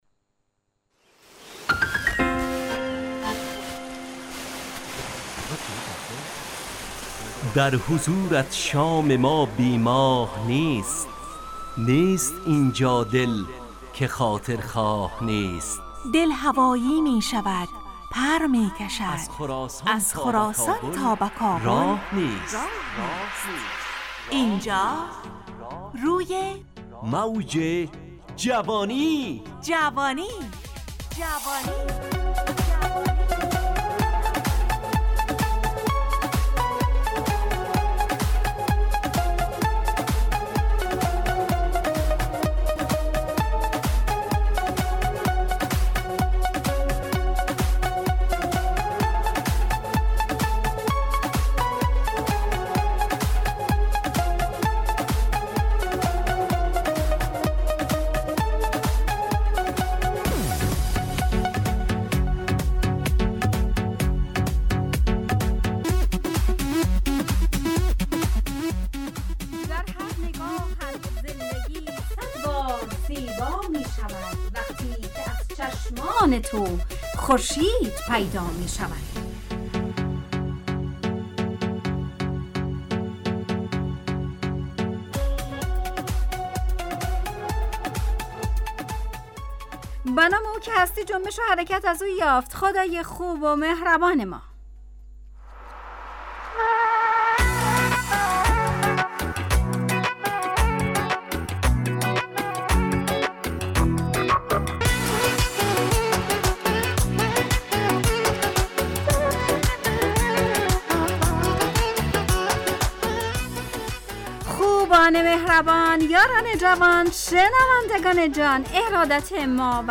روی موج جوانی برنامه ی شاد و عصرانه از رادیو دری از شنبه تا پنجشنبه ازساعت 4:45 الی5:55 به وقت افغانستان با طرح موضوعات روز وآگاهی دهی برای جوانان، و.....
همراه با ترانه و موسیقی